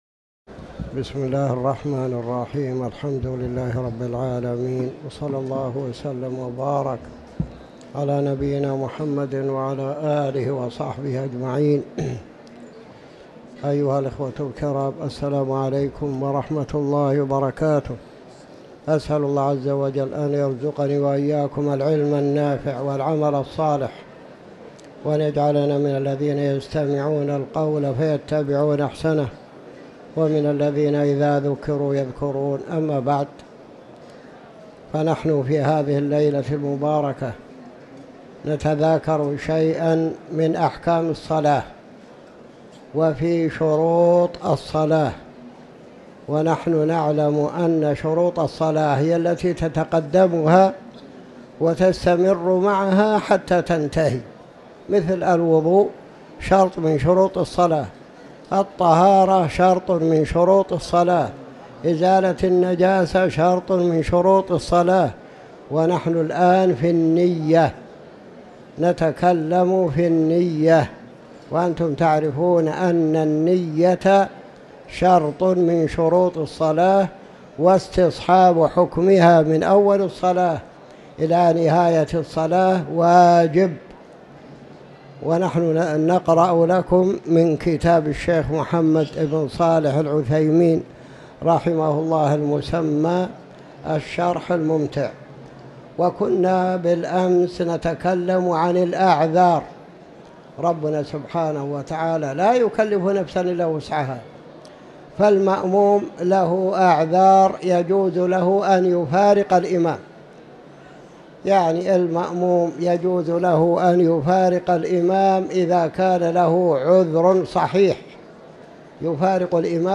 تاريخ النشر ٢٩ جمادى الآخرة ١٤٤٠ هـ المكان: المسجد الحرام الشيخ